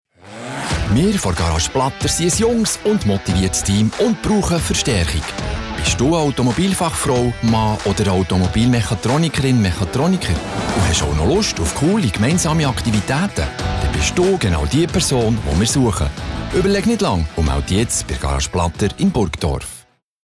Werbung Schweizerdeutsch (BE)